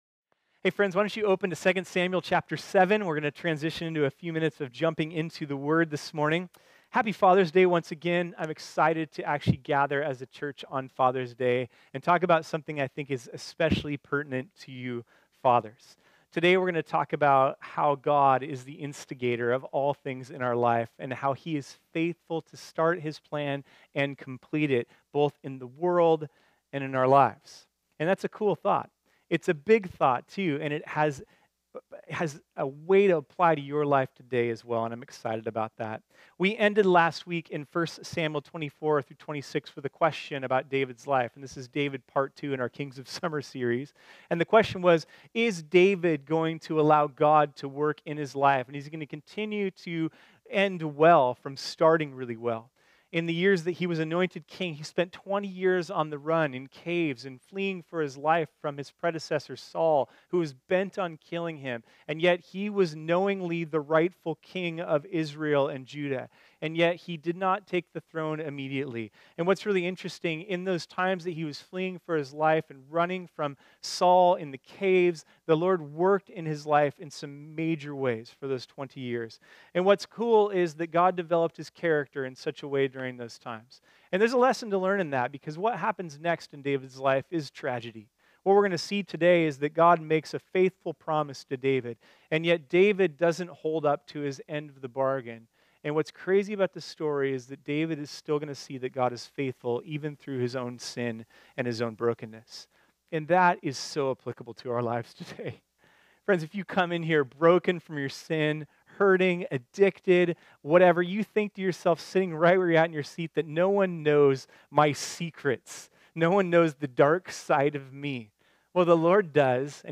This sermon was originally preached on Sunday, June 17, 2018.